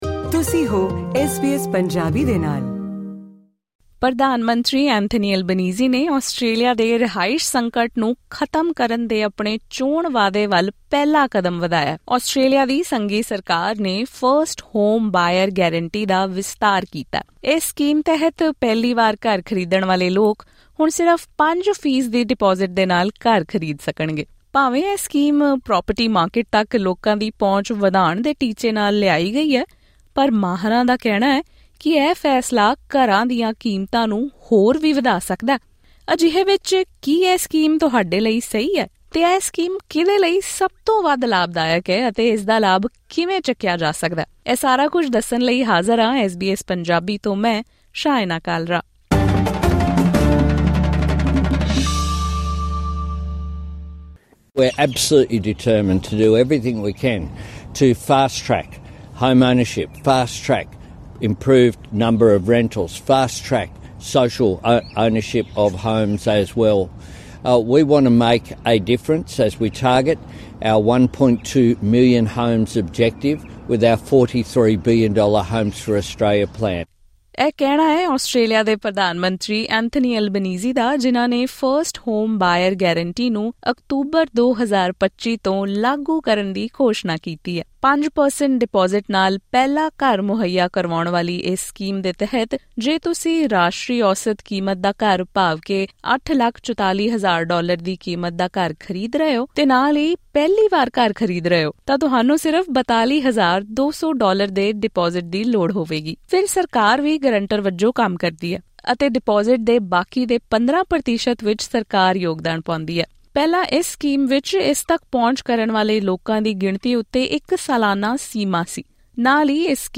ਗੱਲਬਾਤ